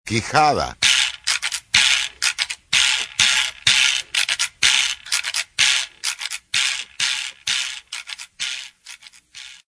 Sonidos de instrumentos Afroperuanos
"Quijada de burro"
quijada.mp3